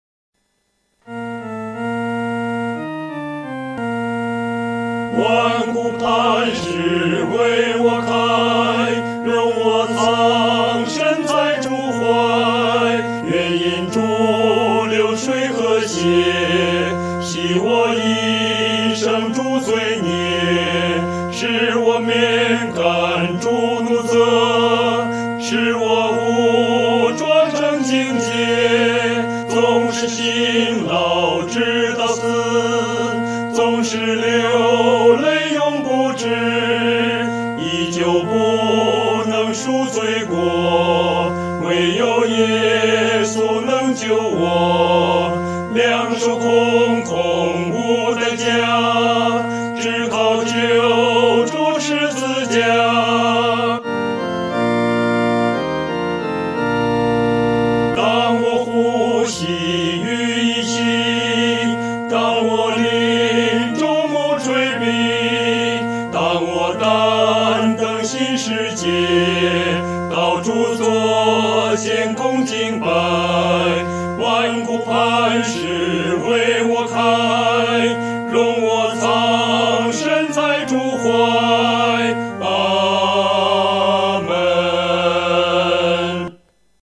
合唱
男高
本首圣诗由网上圣诗班 (环球）录制